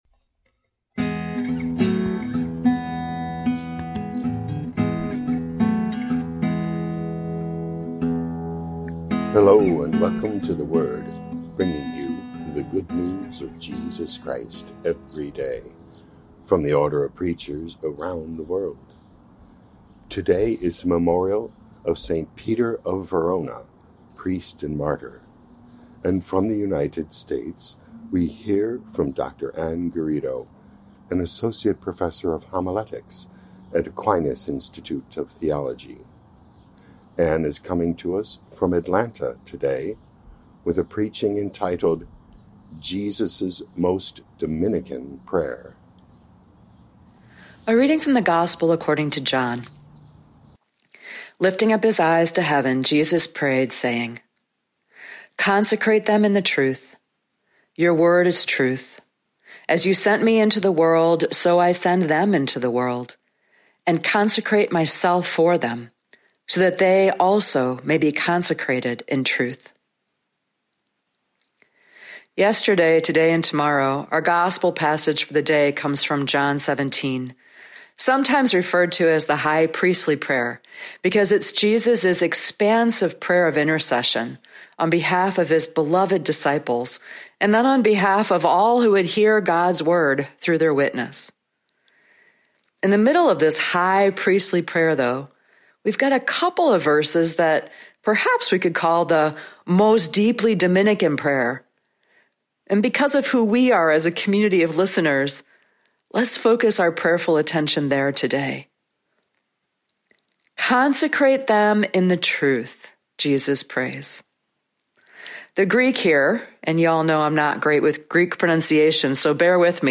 4 Jun 2025 Jesus’ Most Dominican Prayer Podcast: Play in new window | Download For 4 June 2025, Memorial of Saint Peter of Verona, Priest, Martyr, based on John 17:11b-19, sent in from Atlanta, Georgia, USA.
Preaching